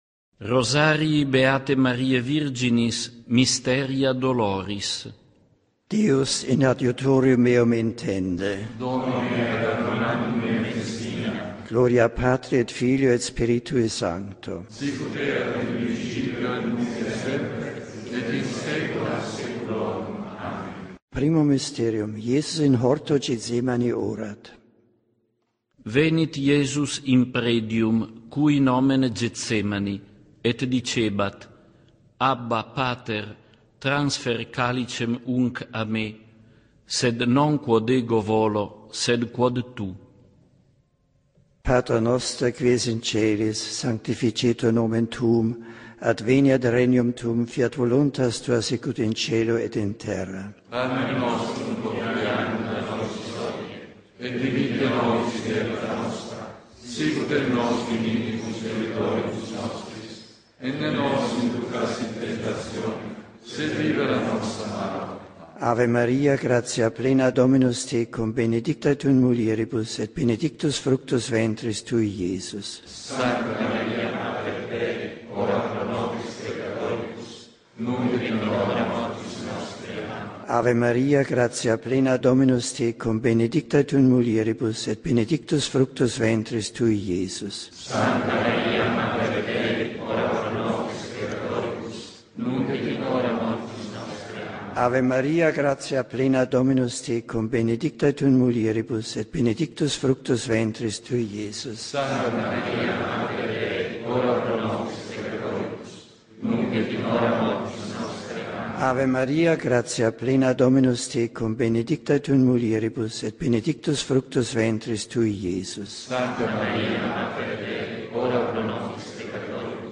Mysteria Doloris (T–F) (audio) Trascription of the Sorroful Mysteries Rosary in Latin as recited by Pope Emeritus Benedict XVI
Rosario-in-Latino-di-Benedetto-XVI-Misteri-Dolorosi.mp3